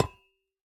Minecraft Version Minecraft Version snapshot Latest Release | Latest Snapshot snapshot / assets / minecraft / sounds / block / copper / break1.ogg Compare With Compare With Latest Release | Latest Snapshot
break1.ogg